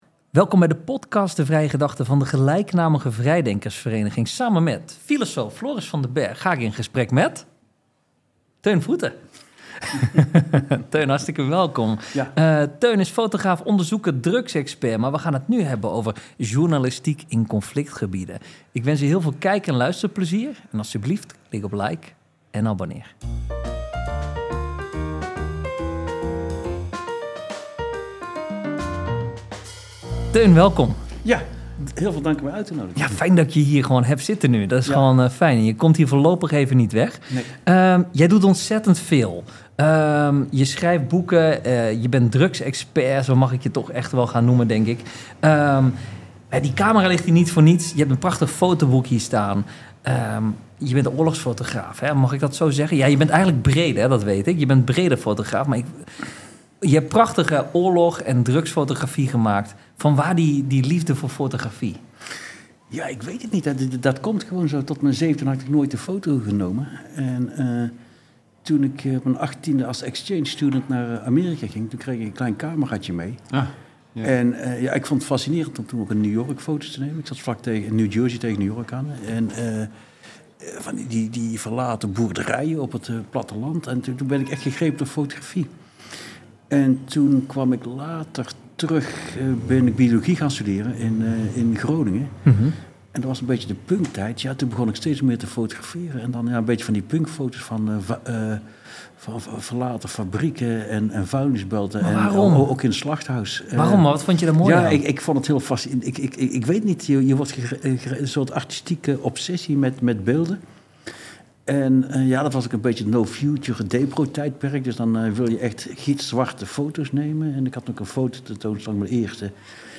in gesprek
Deze podcasts zijn opgenomen met beeld.